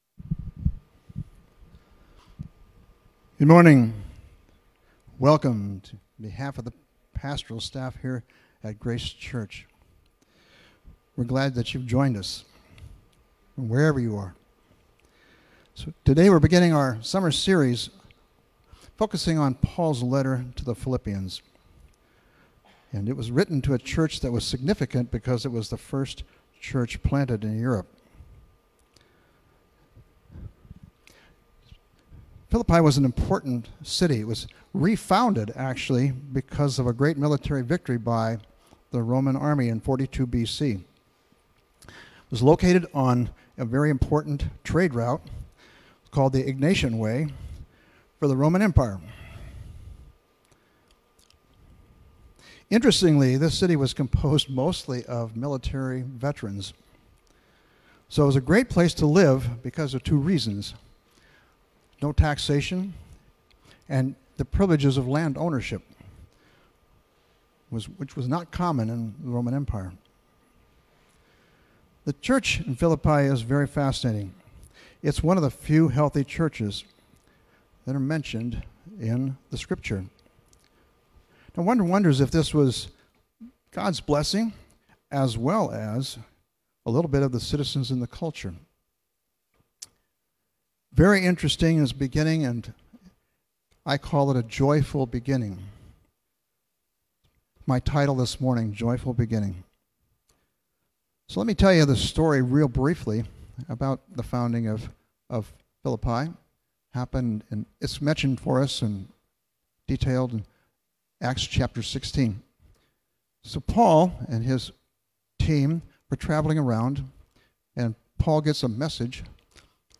May 24, 2020 Joyful Beginnings (05.24.2020) MP3 PDF SUBSCRIBE on iTunes(Podcast) Notes Discussion Sermons in this Series This week we begin a new series about Paul's letter to the Philippians! Join us as we look at the church's origins and lay the groundwork for the rest of the series.